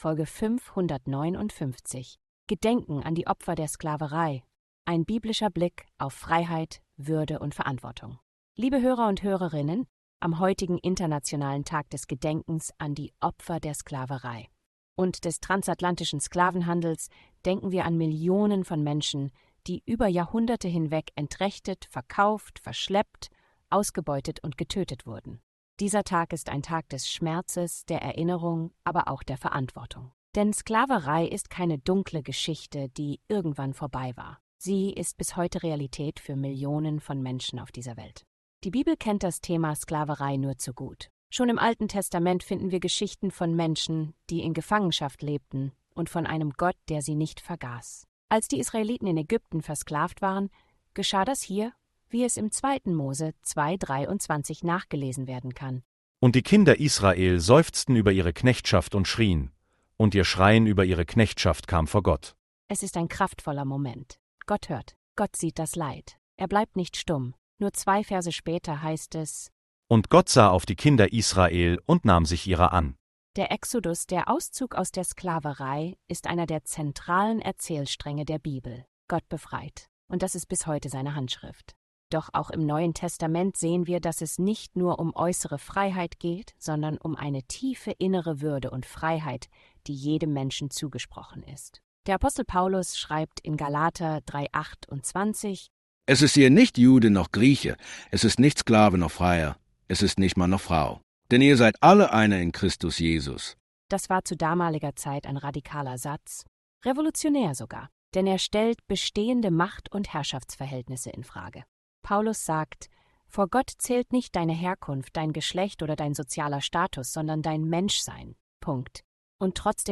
Andacht, die wachrüttelt.